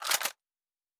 pgs/Assets/Audio/Sci-Fi Sounds/Weapons/Weapon 15 Foley 1.wav at master
Weapon 15 Foley 1.wav